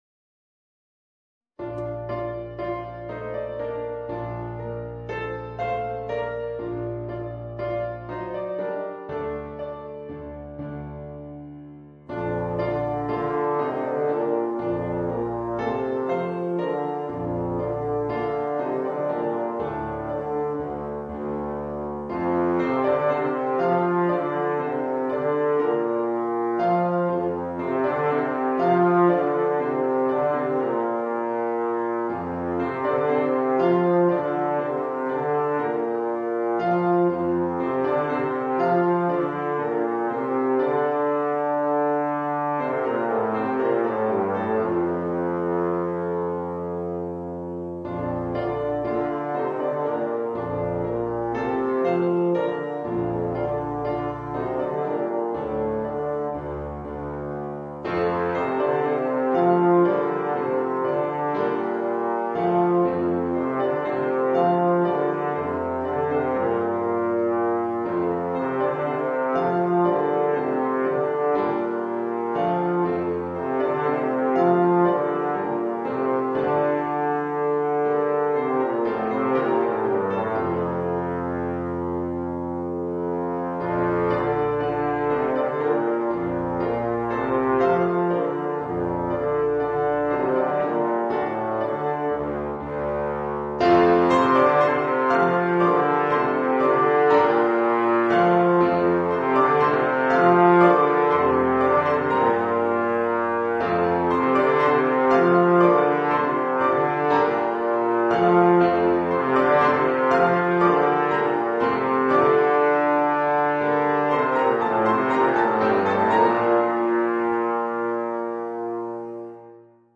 Voicing: Bass Trombone and Piano